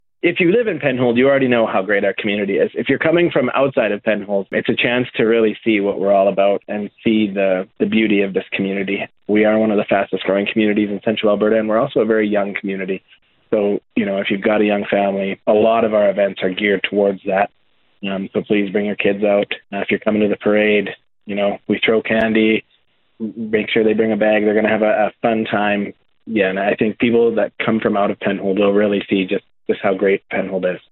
Penhold Mayor Mike Yargeau spoke with CFWE about what attendees can expect when they head down to Penhold’s Fall Festival Celebration.